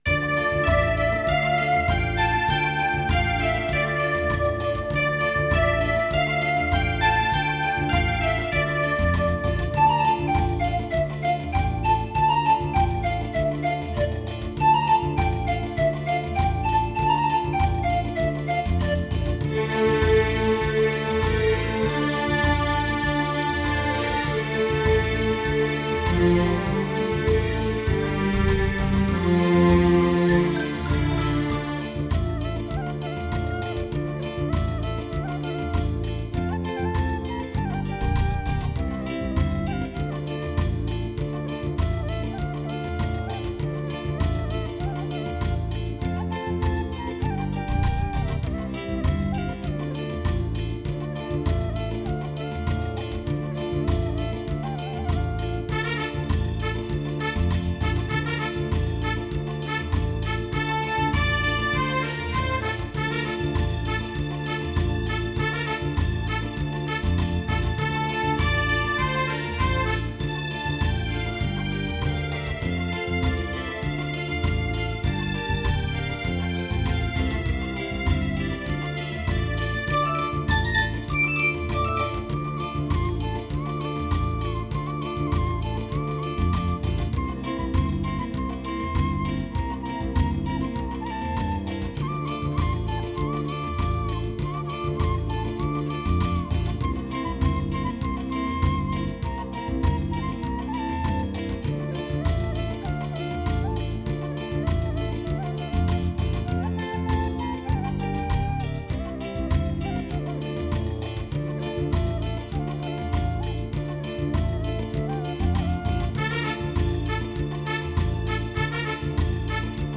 Minus One Tracks